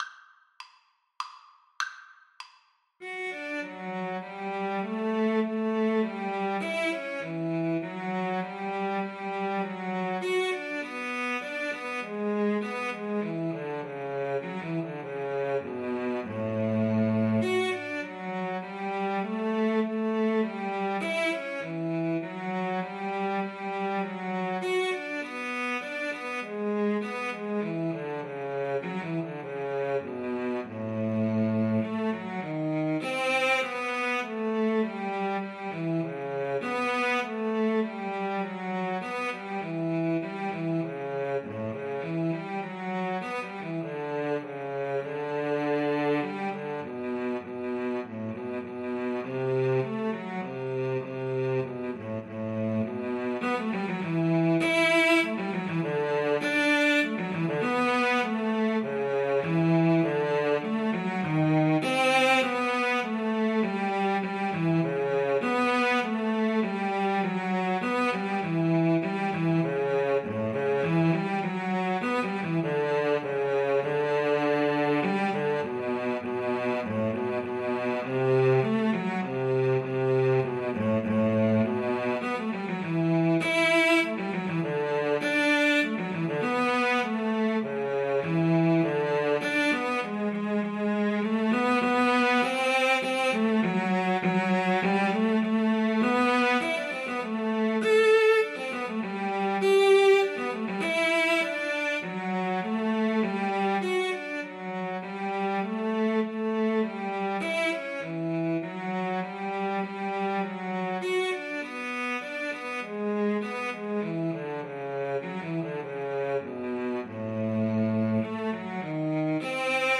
Free Sheet music for Cello Duet
Cello 1Cello 2
D major (Sounding Pitch) (View more D major Music for Cello Duet )
3/4 (View more 3/4 Music)
Classical (View more Classical Cello Duet Music)